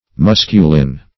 Musculin \Mus"cu*lin\, n. [L. musculus a muscle.] (Physiol.